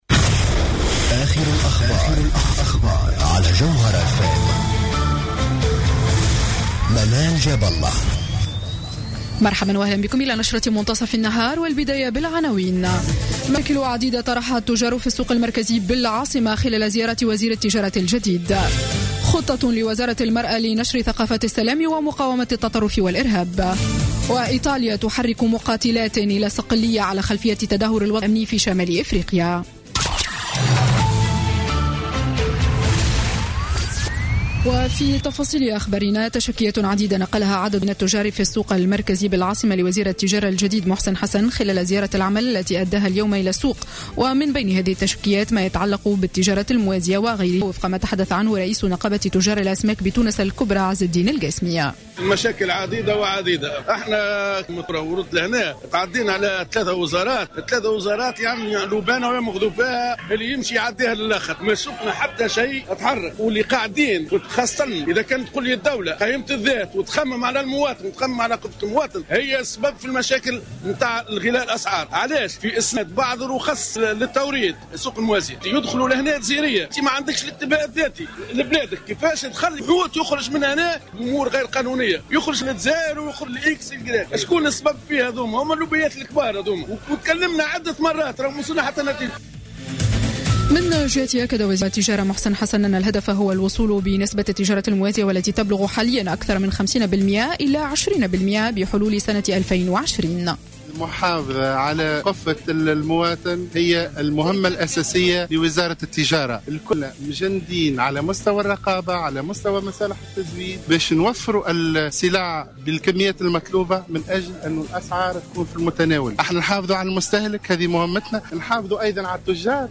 Journal Info 12h00 du samedi 16 Janvier 2016